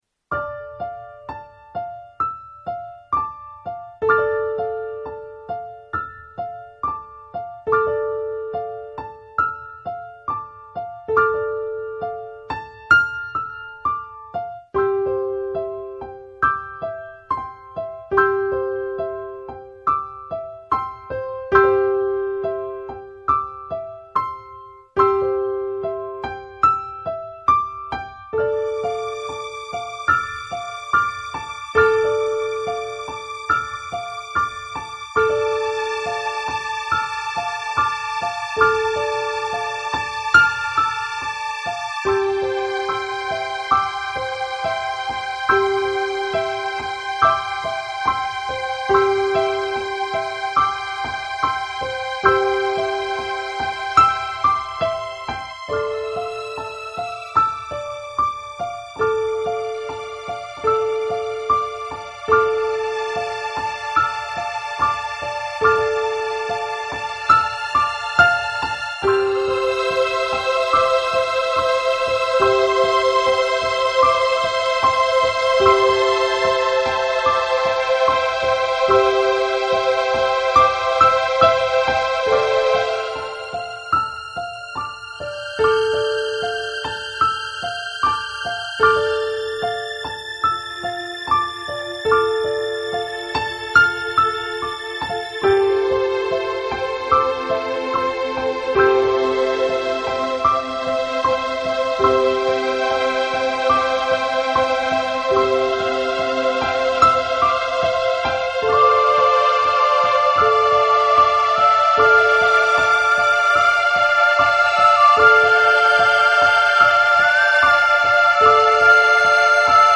5 Minuten Ruhe mit unserer Entspannungsmusik.
Entspannung für die 3. Woche: